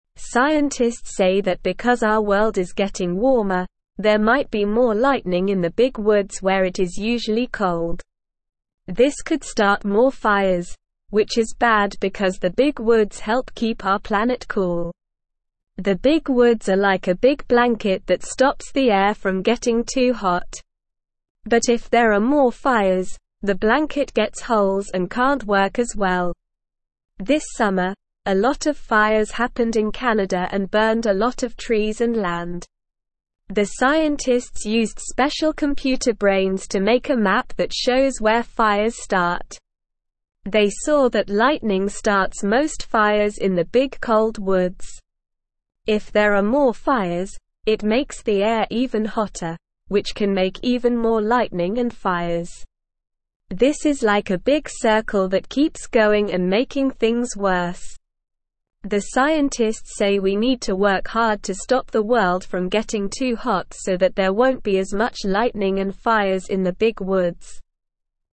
Slow
English-Newsroom-Beginner-SLOW-Reading-More-Lightning-and-Fires-in-Cold-Woods.mp3